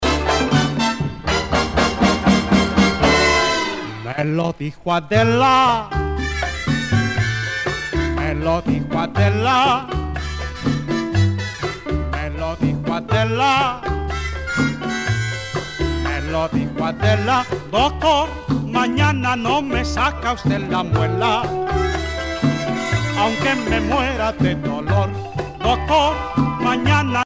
danse : mambo
Pièce musicale éditée